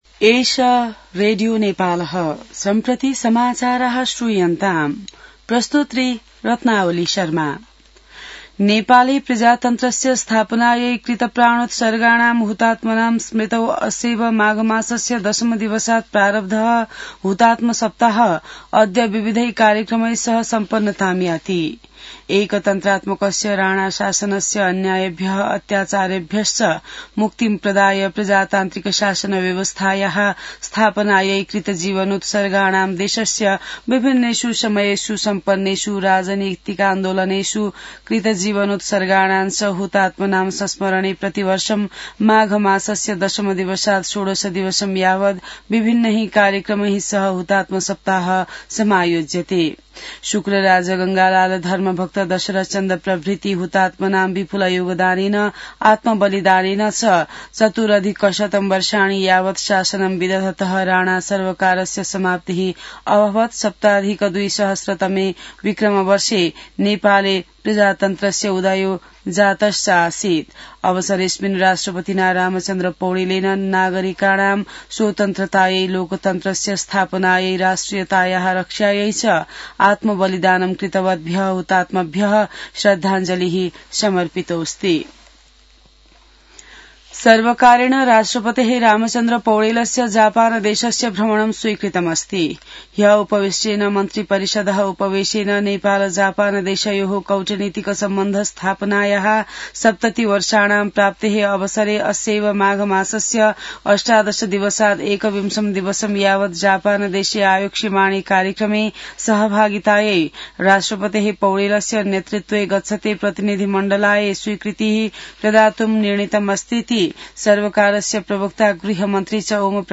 संस्कृत समाचार : १६ माघ , २०८२